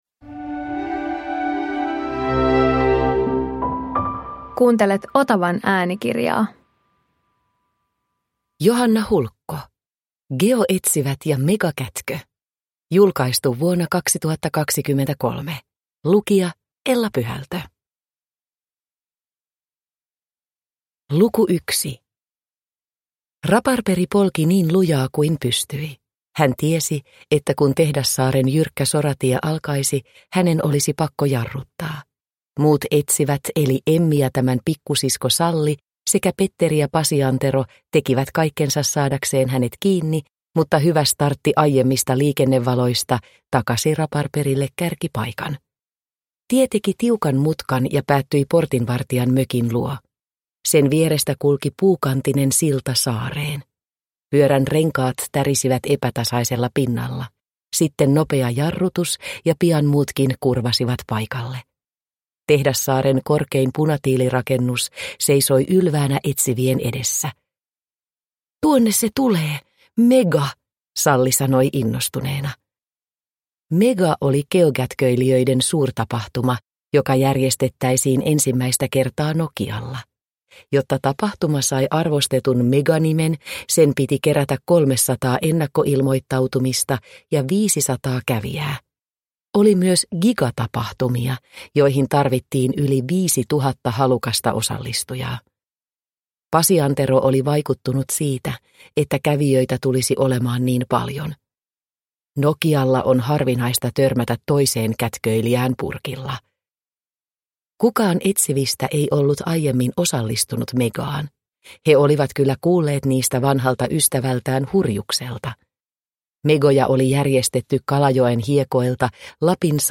Geoetsivät ja megakätkö – Ljudbok – Laddas ner